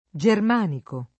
vai all'elenco alfabetico delle voci ingrandisci il carattere 100% rimpicciolisci il carattere stampa invia tramite posta elettronica codividi su Facebook germanico [ J erm # niko ] etn.; pl. m. ‑ci — sim. il pers. m. stor.